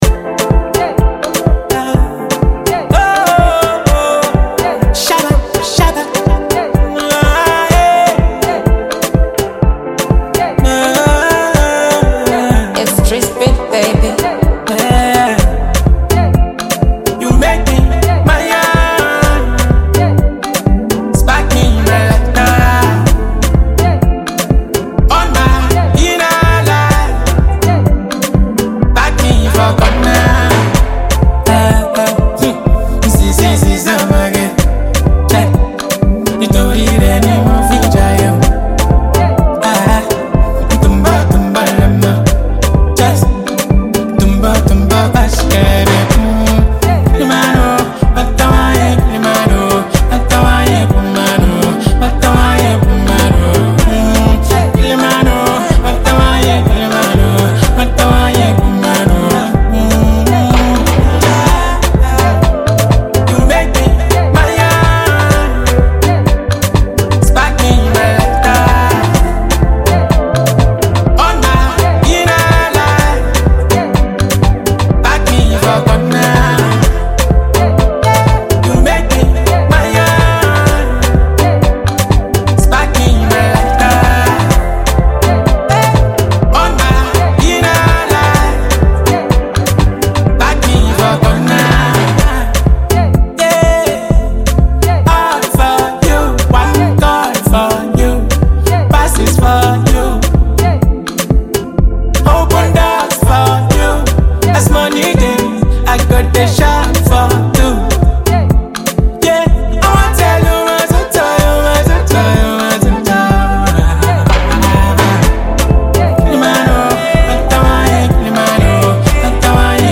Nigerian Afro-pop
With its mellow Afrobeat-inspired production